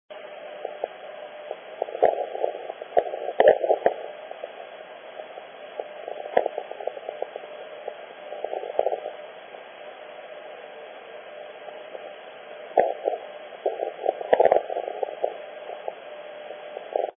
VTX2 17.0 kHz India was coming through nicely pre dawn so took a listen for JJY 40 kHz Japan not expecting to hear much. Was surprised to get 'weak but reasonable' copy of their ID this early in the season.